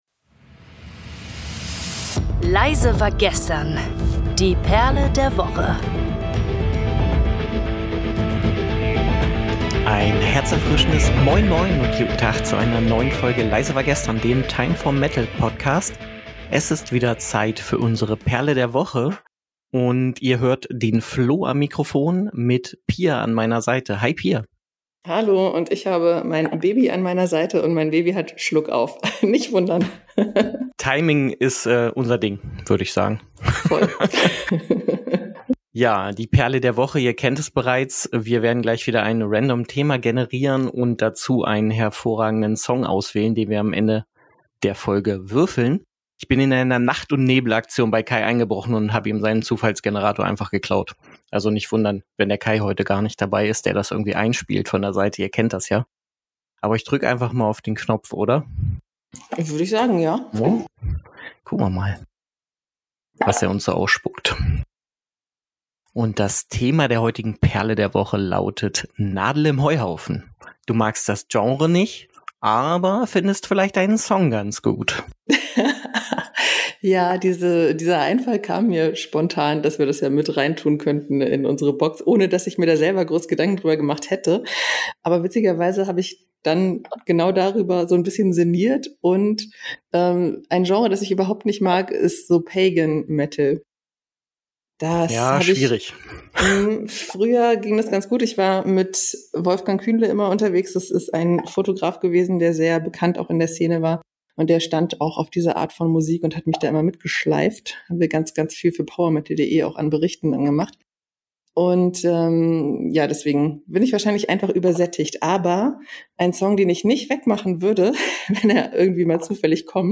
Neben unserem Format, bei dem wir unsere Gäste mit dem Themenroulette Informationen und Meinungen entlocken, die nicht immer in der Komfortzone liegen liefern wir euch mit dem neuen Format "Der Perle Der Woche" wöchentlich drei Songs aus dem Metal und Rock. Zu Beginn der kurzweiligen Episoden sucht sich der Zufallsgenerator ein Thema raus, welches dann als Basis für jeweils eine Empfehlung der Moderatoren herangezogen wird.